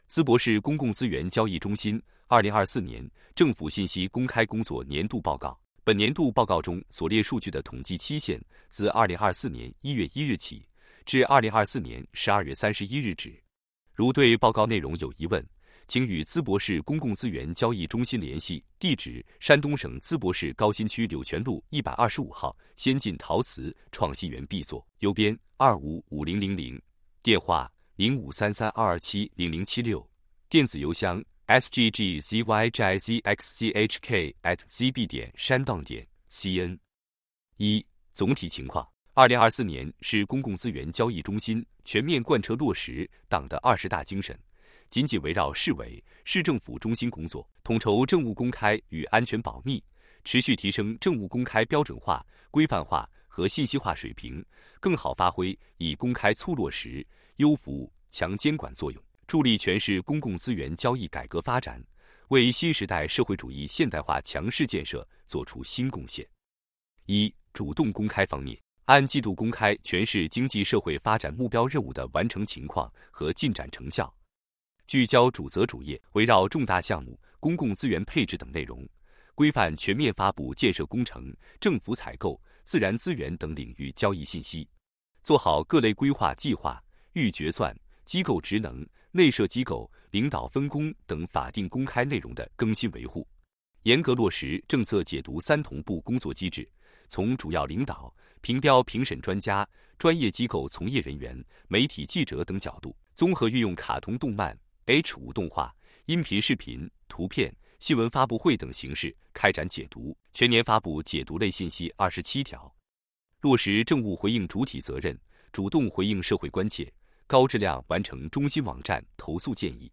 语音播报   |   图片解读